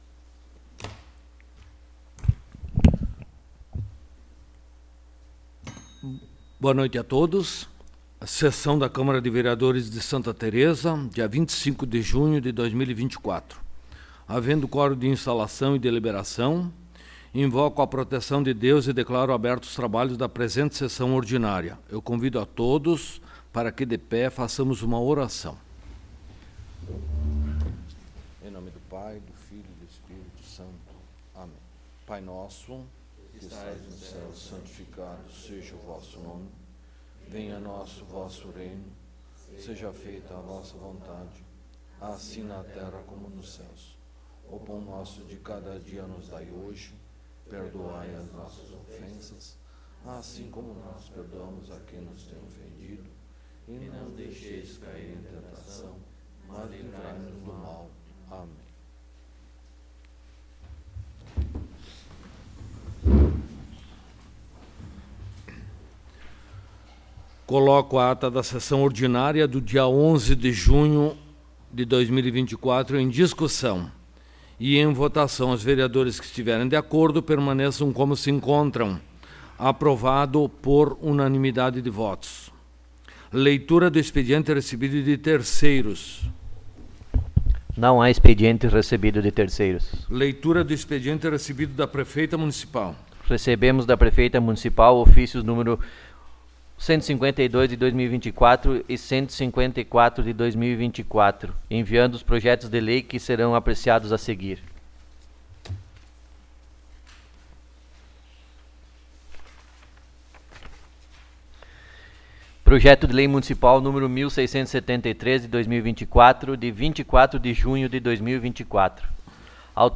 10ª Sessão Ordinária de 2024
Áudio da Sessão